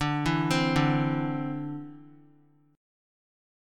D7sus2 Chord